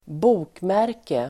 Uttal: [²b'o:kmär:ke]